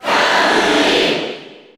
Category: Crowd cheers (SSBU) You cannot overwrite this file.
Corrin_Cheer_Japanese_SSBU.ogg